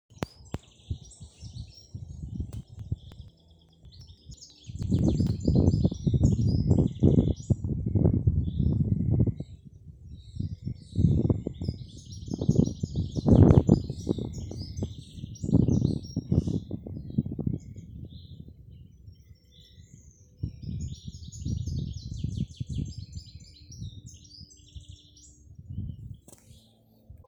Common Treecreeper, Certhia familiaris
Ziņotāja saglabāts vietas nosaukumsLīgatnes pag., Ķempji
StatusSinging male in breeding season